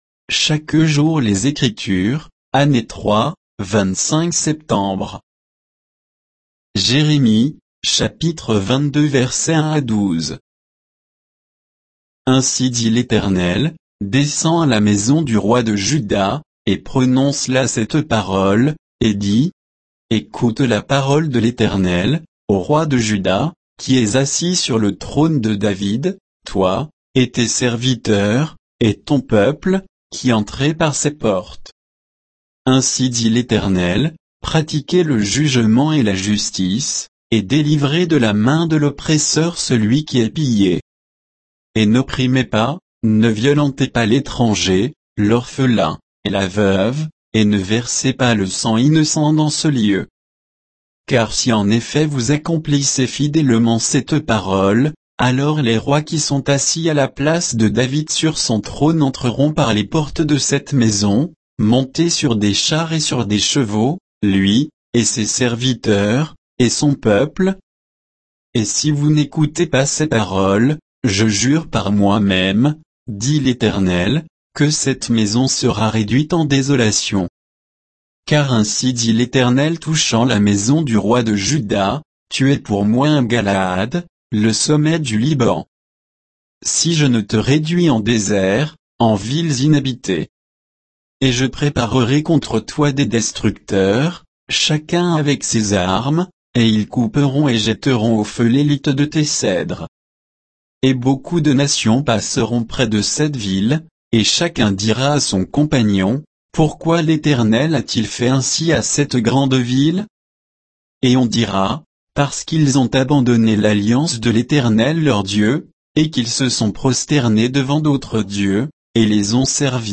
Méditation quoditienne de Chaque jour les Écritures sur Jérémie 22